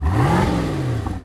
dviratel_gas1.ogg